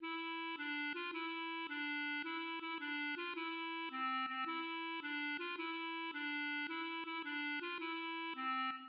Nursery rhyme
"Bye, baby Bunting" (Roud 11018) is an English-language nursery rhyme and lullaby.[citation needed]